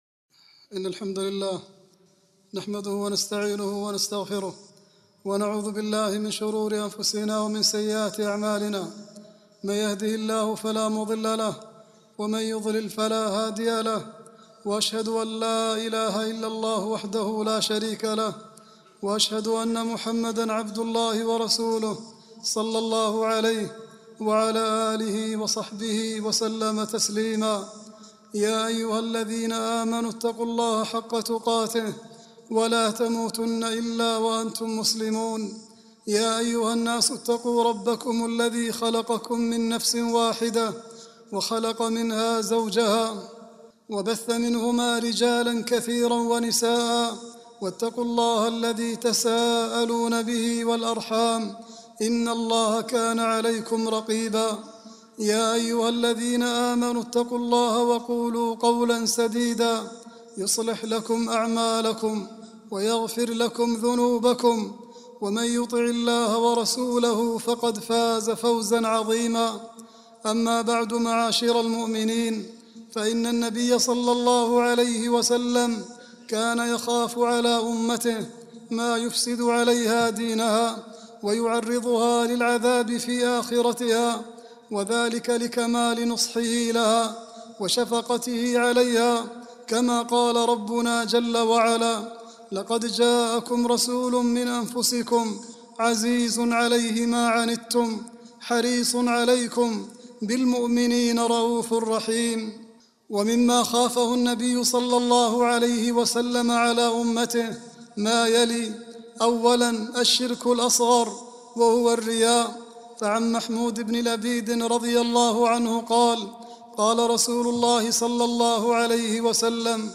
العنوان : خصال خافها النبي صلى الله عليه وسلم على أمته خطبة
khutbah-14-6-39.mp3